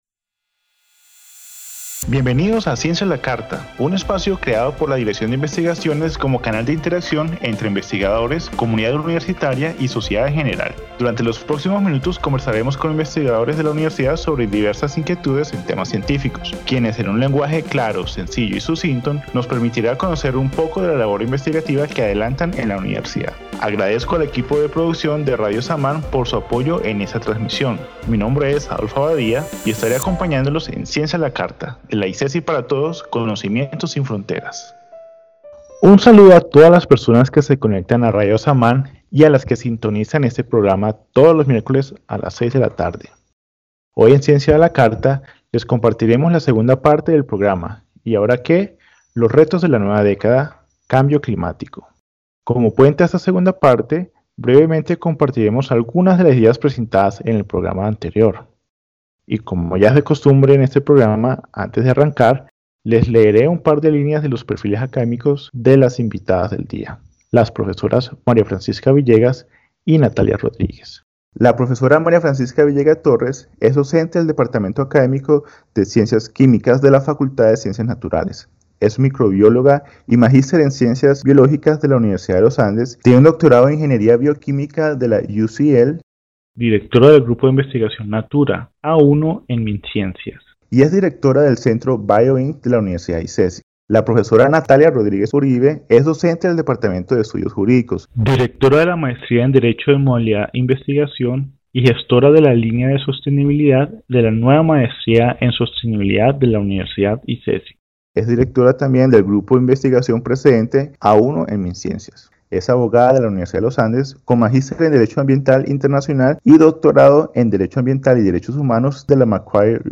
En el primer momento del programa, las investigadoras invitadas tendrán un espacio para reaccionar, libremente, al tema del día y, posteriormente, se traerán a la mesa preguntas formuladas previamente por el público para ser abordadas por medio del diálogo con las expertas invitadas.